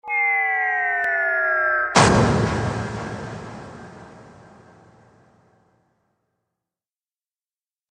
دانلود صدای بمب 9 از ساعد نیوز با لینک مستقیم و کیفیت بالا
جلوه های صوتی